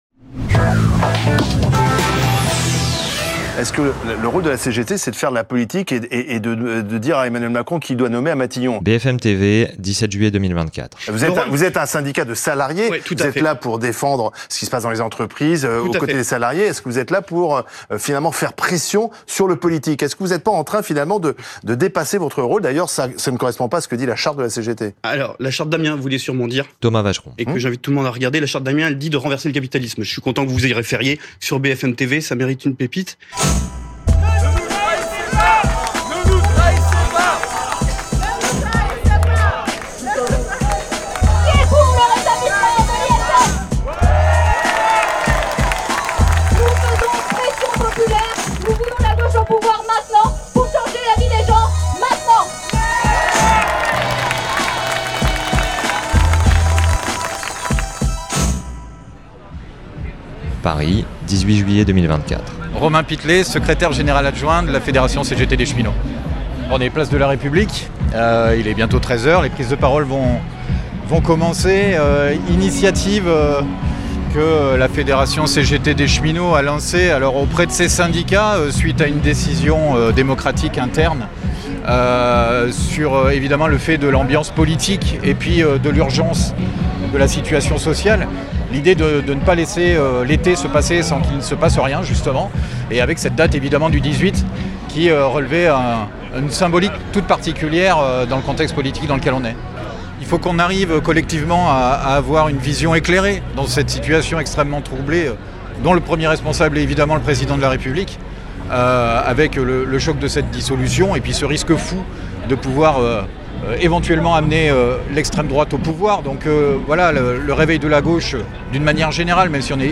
À Paris, c’est symboliquement place de la République qu’avait lieu la mobilisation, à l’initiative de la CGT Cheminots. Quel rôle politique pour les syndicats ? Quelle inspiration syndicale pour le moment politique ?